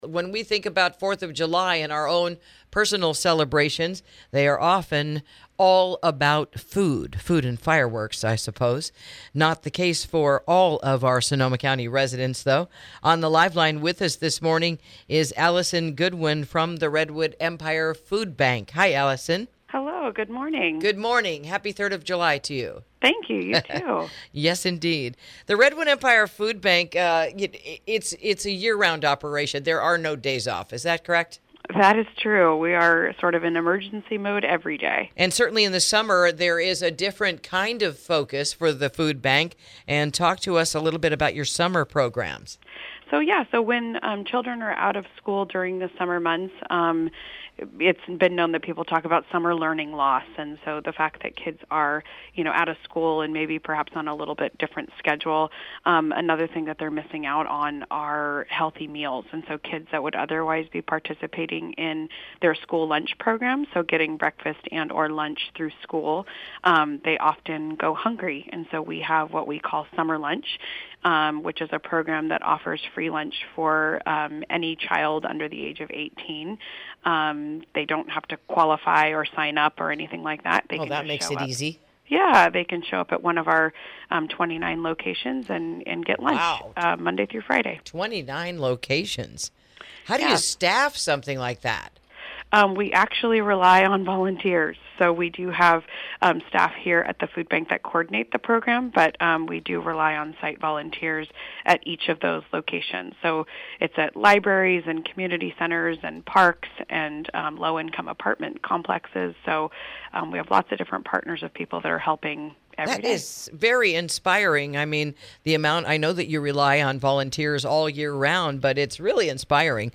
Interview: The Redwood Empire Food Bank’s Summer Program for Kids